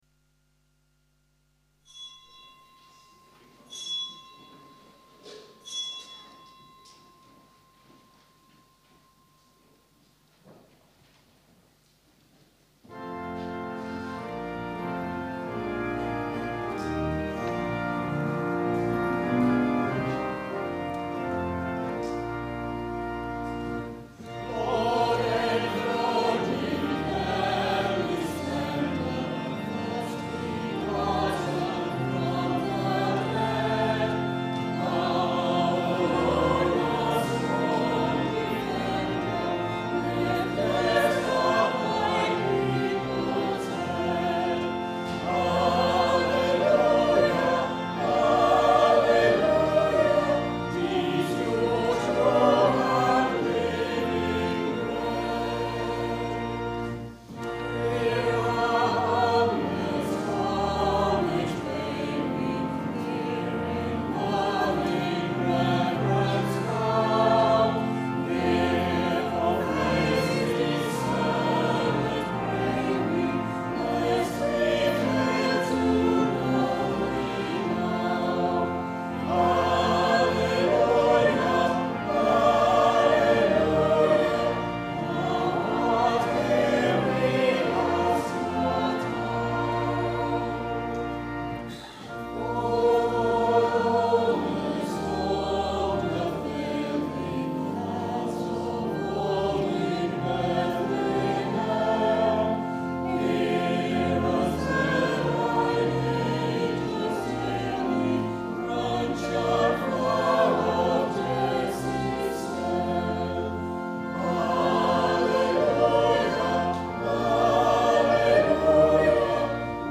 A sung Eucharist streamed live from St Mary's Church, Whitkirk for the nineteenth Sunday after Trinity.